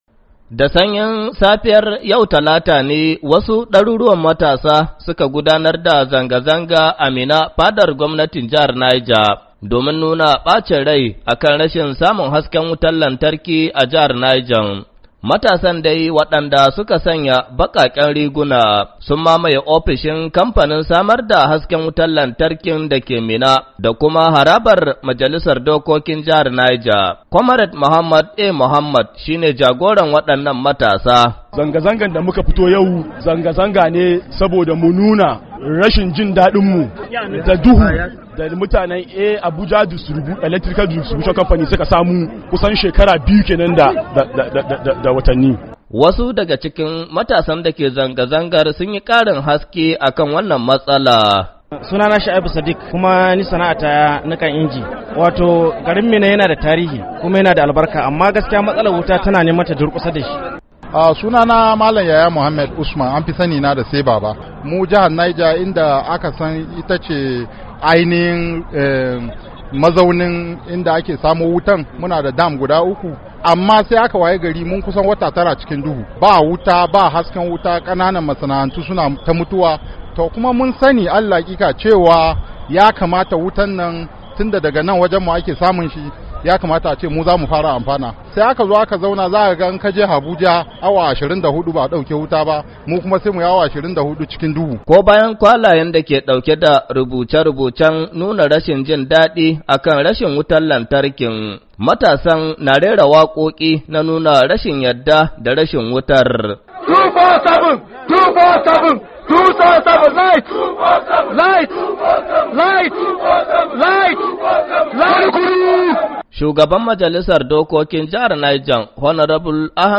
Ko bayan kwalayen da ke dauke da rubuce rubucen nuna rashin jin dadi akan rashin wutar lantarkin, matasan na rera wakoki na nuna rashin jin dadi da yadda da rashin wutar.